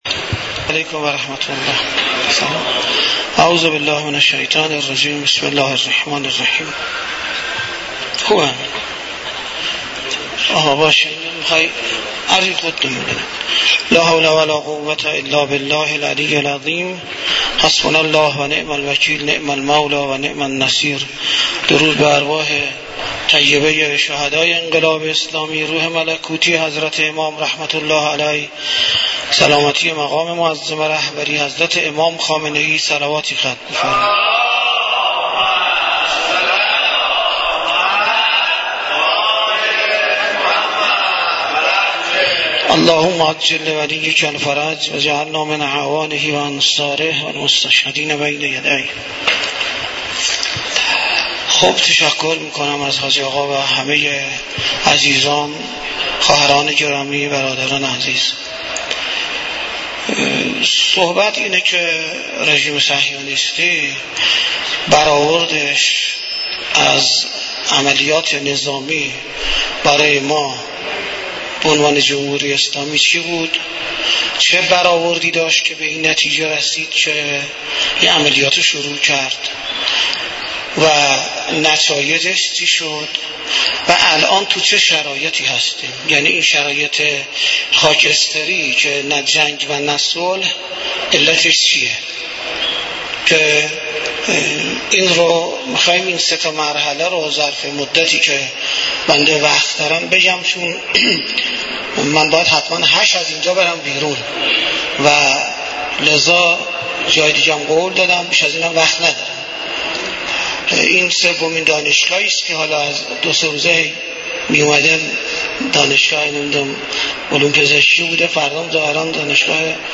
برگزاری مراسم عزاداری حضرت اباعبدالله الحسین علیه السلام در دومین روز محرم الحرام در مسجد دانشگاه کاشان - نهاد نمایندگی مقام معظم رهبری در دانشگاه کاشان
مراسم عزاداری حضرت اباعبدالله الحسین علیه السلام همراه با قرائت زیارت عاشورا و مدّاحی در دانشگاه کاشان برگزار شد.